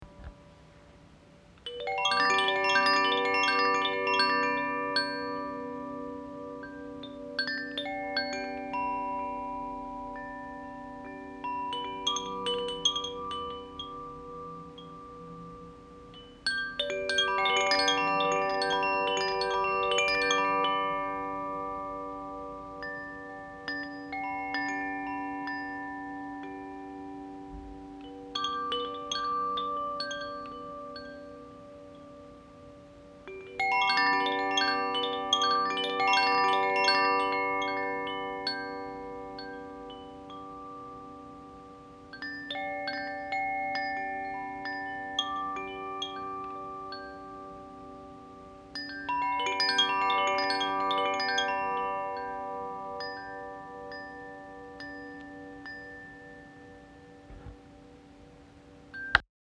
Ils sont prisés non seulement pour leurs qualités sonores mais aussi leur capacité à créer des environnements paisibles et harmonieux.
Il en existe 4 types, chacun accordé sur une gamme différente et inspiré par les quatre éléments.
Chaque carillon est accordé sur une gamme spécifique, créant des mélodies uniques et harmonieuses qui reflètent les caractéristiques de l’élément correspondant.
Carillon-Ignis.mp3